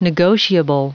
Prononciation du mot negotiable en anglais (fichier audio)
Prononciation du mot : negotiable